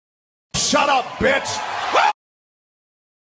Stopwatch Sound Effect Download: Instant Soundboard Button
Stopwatch Sound Button - Free Download & Play